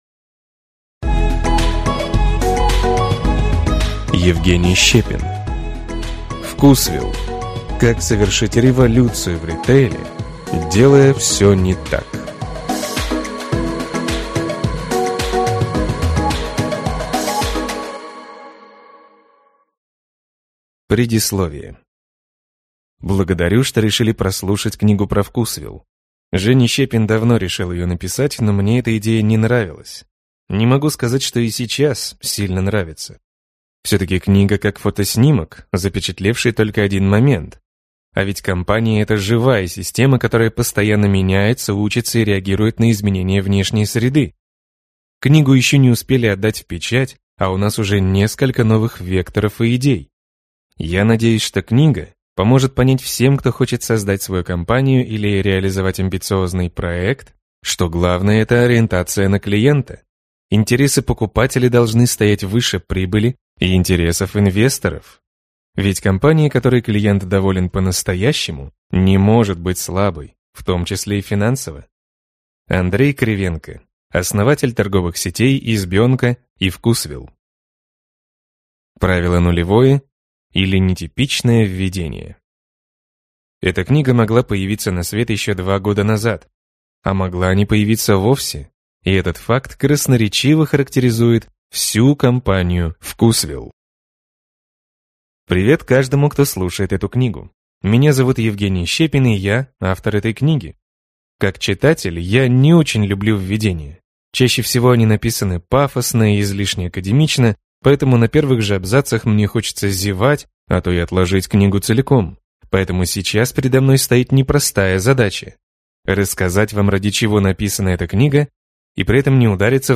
Аудиокнига ВкусВилл: Как совершить революцию в ритейле, делая всё не так | Библиотека аудиокниг